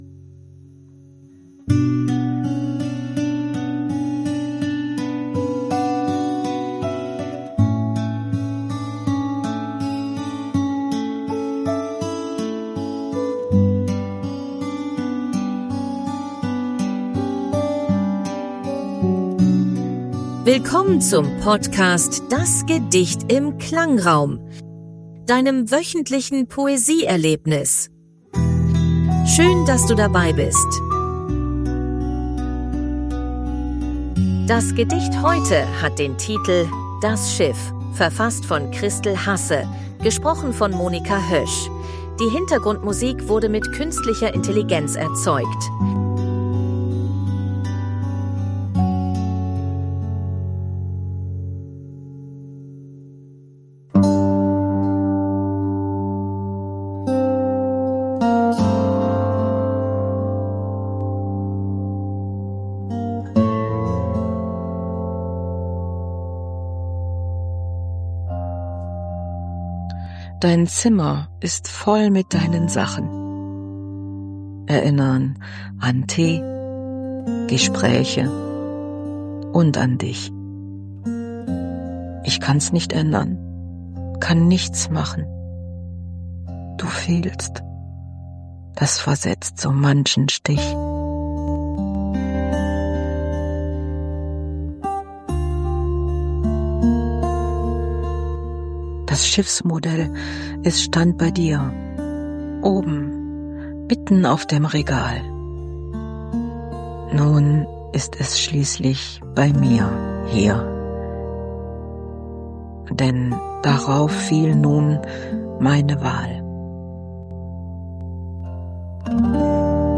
Die Hintergrundmusik wurde mit KI